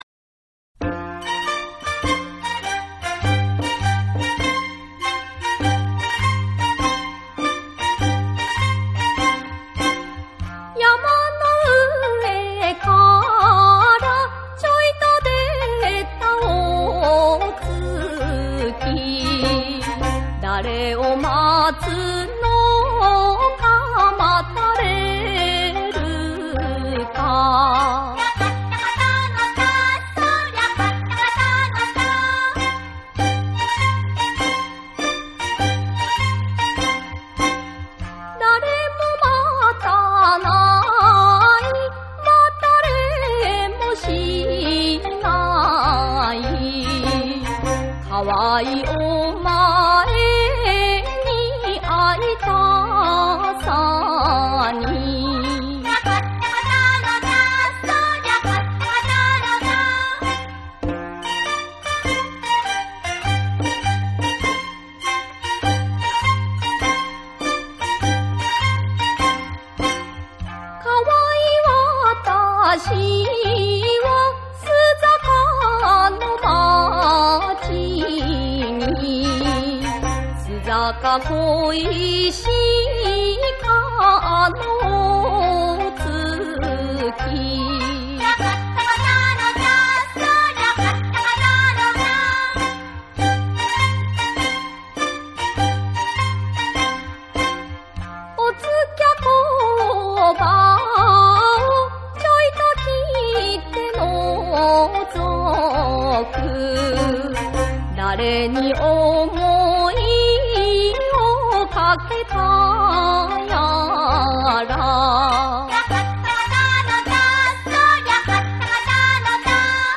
「正調」須坂小唄（標準の踊り）